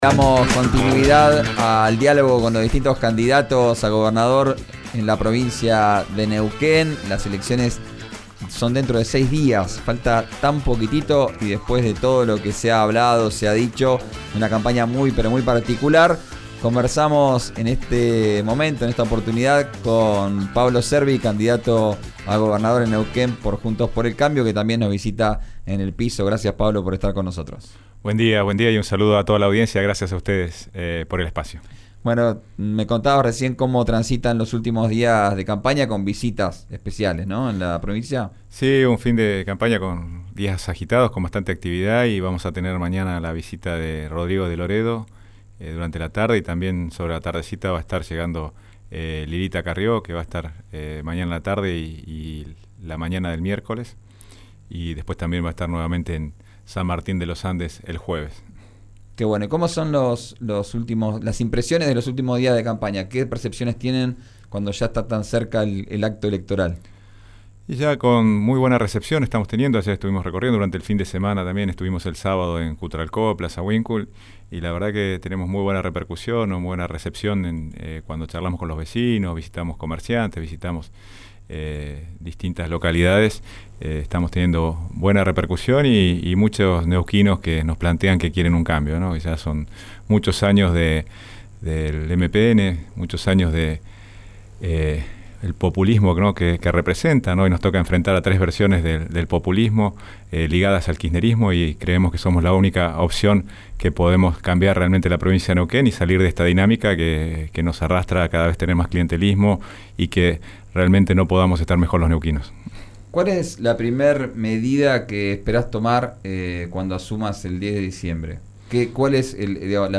El candidato a gobernador por Juntos por el Cambio Neuquén afirmó que esa será su primera medida. Escuchá la entrevista completa en RÍO NEGRO RADIO.
Pablo Cervi, diputado nacional y candidato a gobernador de Neuquén por Juntos por el Cambio Neuquén, visitó el estudio de RÍO NEGRO RADIO.